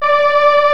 STRINGS  2.1.wav